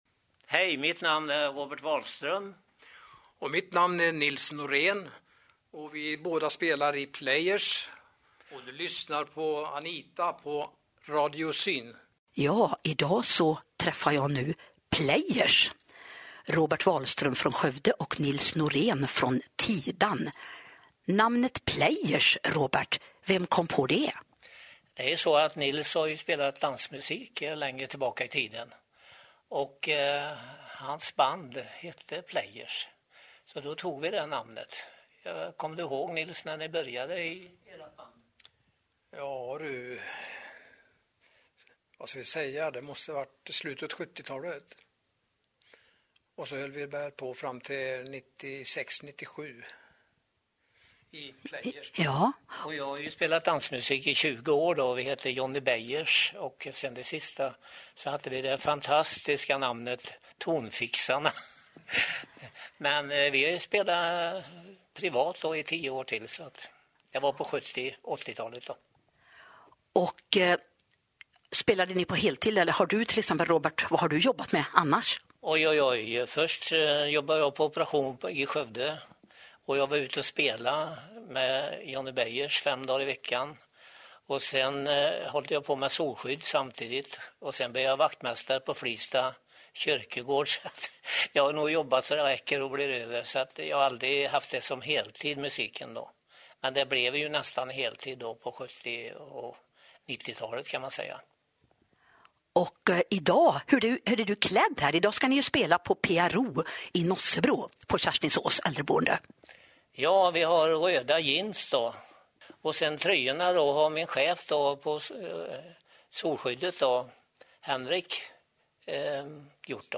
Playersintervju.MP3
Players-intervju.mp3